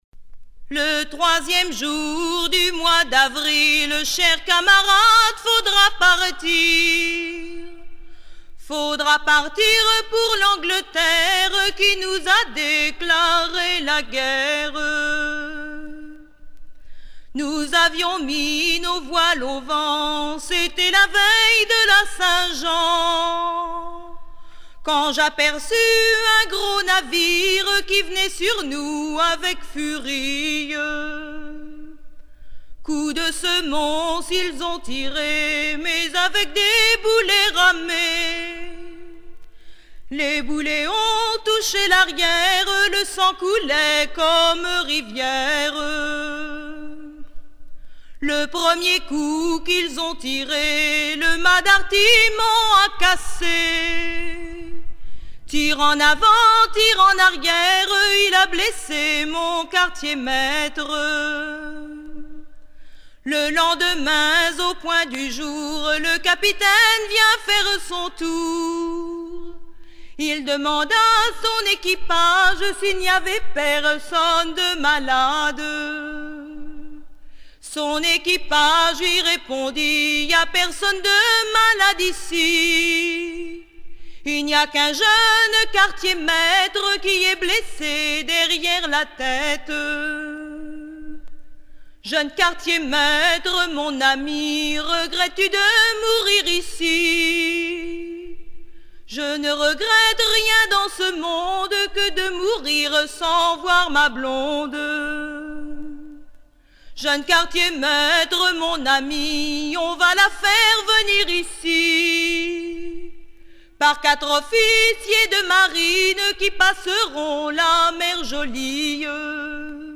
Version recueillie dans les années 1970 en pays de Loudéac
Genre strophique